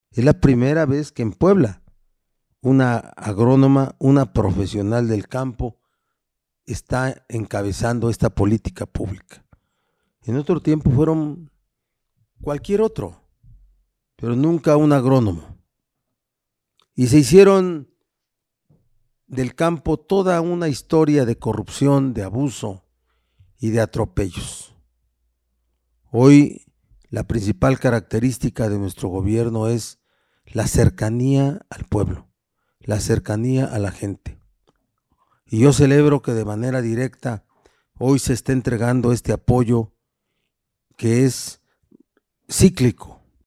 En su mensaje virtual, el mandatario resaltó que actualmente la Secretaría de Desarrollo Rural (SDR) está encabezada por una mujer agrónoma, situación nunca antes vista.